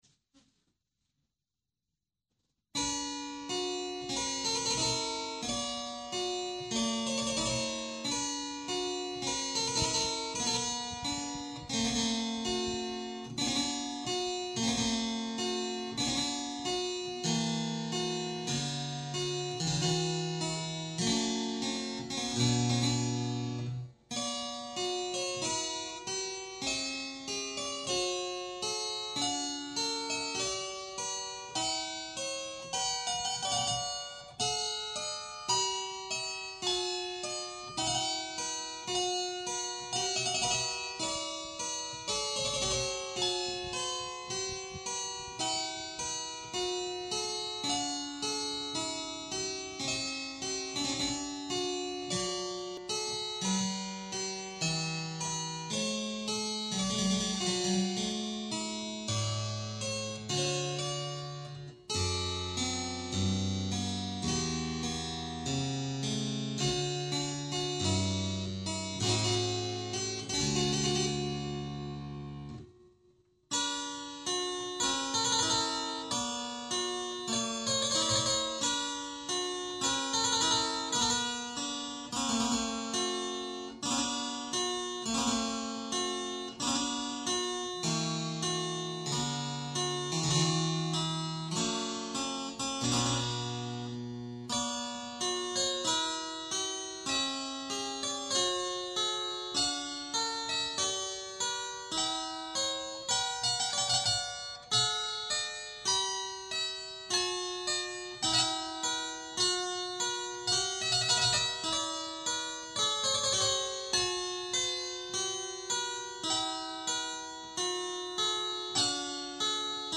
L'interprète demande l'indulgence pour les imperfections techniques dues à son jeu amateur et parfois malhabile !
pièce en ut mineur, le ton de la déploration, en deux parties avec reprises.
"Languissamment".
La3=405Hz, tempérament de Bach-Kellner.
(version courte, sans reprise,
au clavier supérieur)
* Essai d'enregistrement "masterisé" (renforcement des aigus et des basses) peu convaincant !